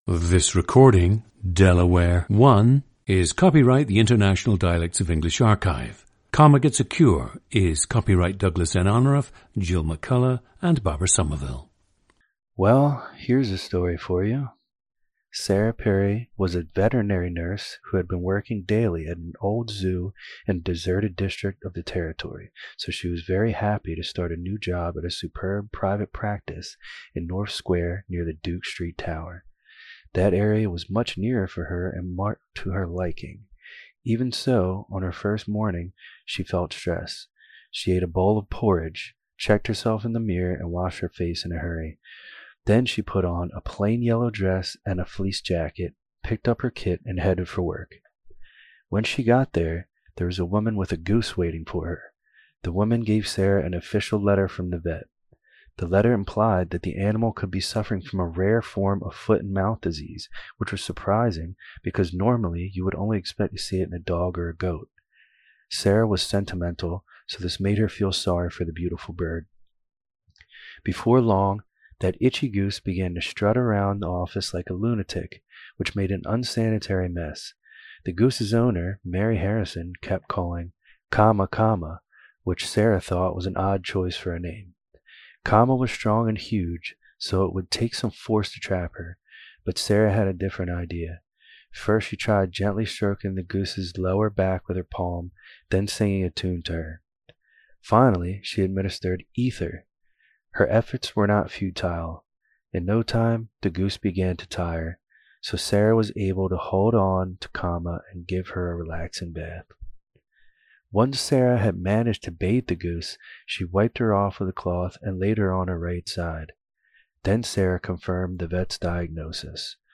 GENDER: male
OTHER INFLUENCES ON SPEECH: none
The subject has an even tone, with almost no vocal fry. His dialect is closer to General American than to any type of regional Delaware sound.
The recordings average four minutes in length and feature both the reading of one of two standard passages, and some unscripted speech.